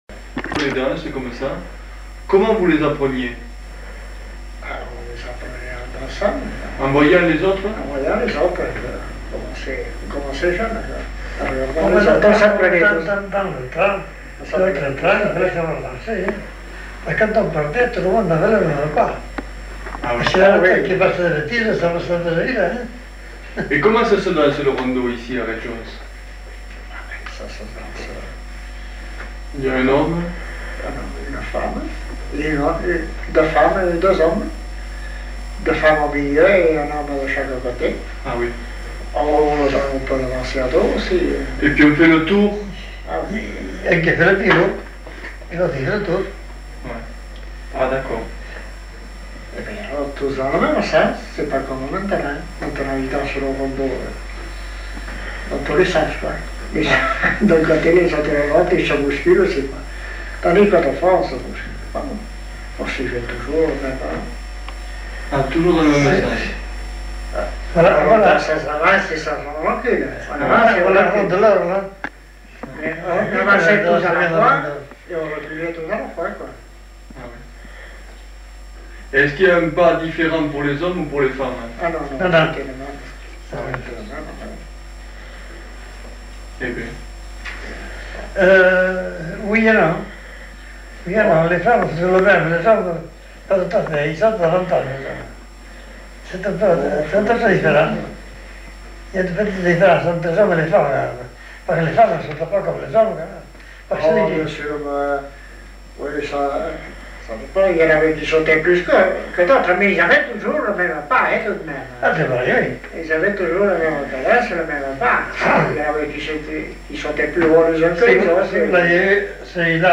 Aire culturelle : Petites-Landes
Genre : témoignage thématique